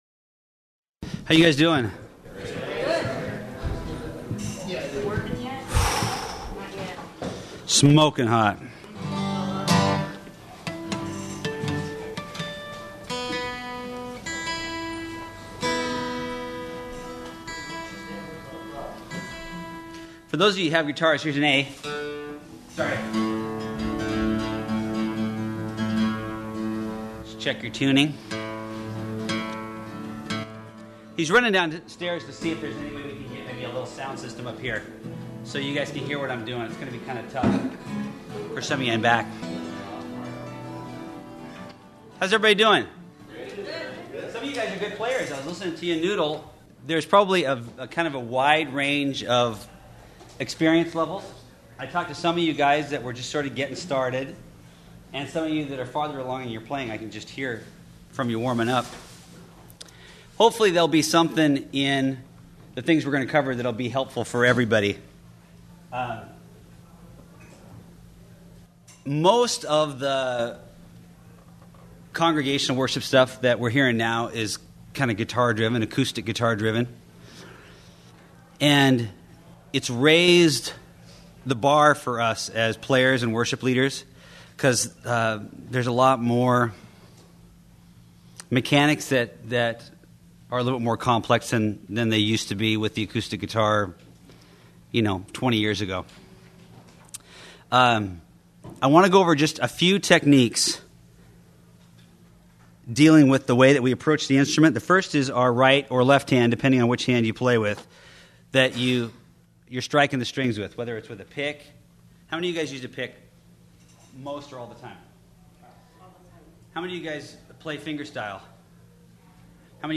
Acoustic Guitar – Calvary Chapel Worship
Series: 2006 Calvary Chapel Worship Leader Conference
Service Type: Workshop